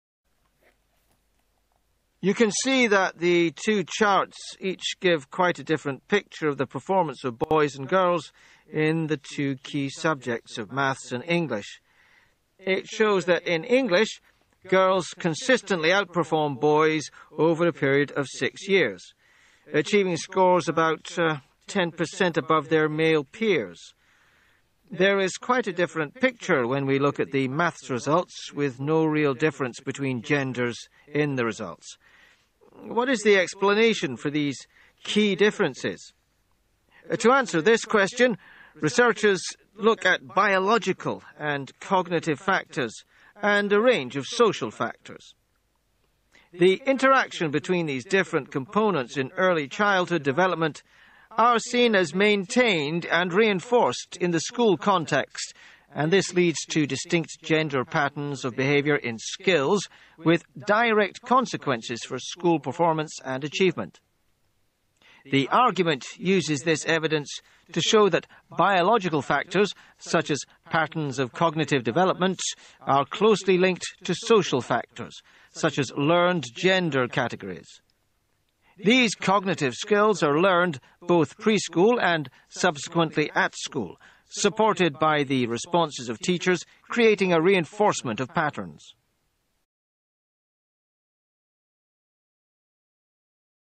Q25. PTE Retell Lecture